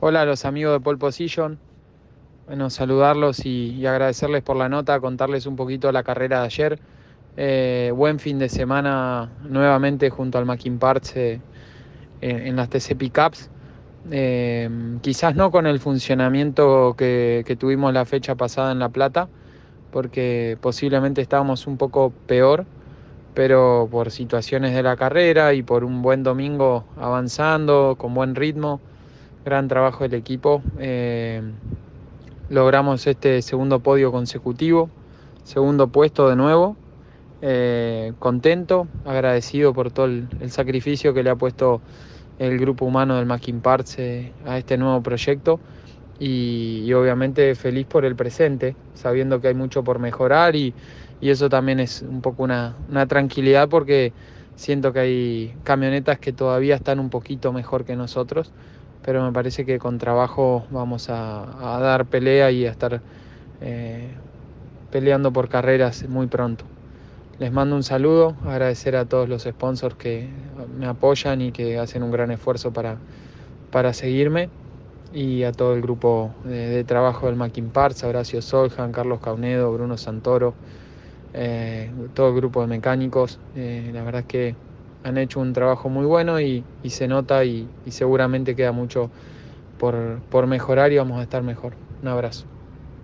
El rionegrino pasó por los micrófonos de Pole Position y manifestó su alegría de haber conseguido su segundo podio consecutivo dentro de las TC Pickup con la Toyota Hilux del equipo Maquin Parts.